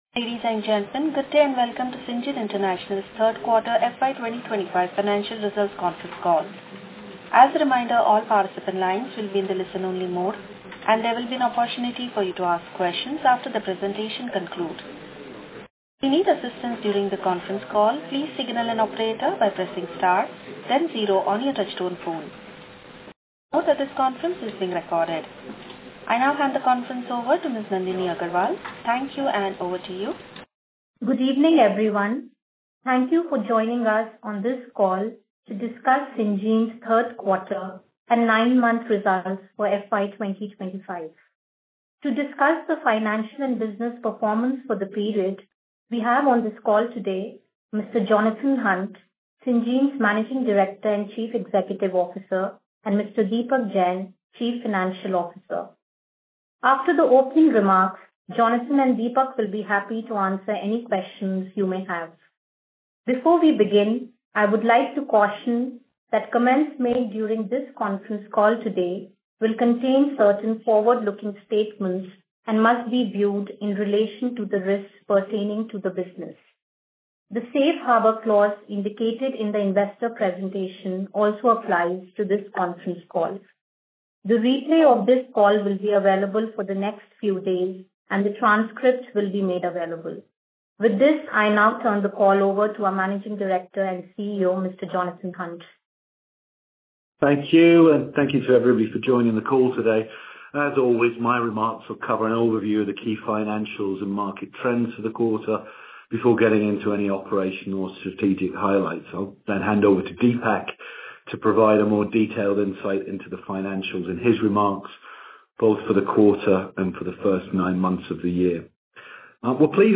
Concalls
IR-Call-Audio-Q3FY25.mp3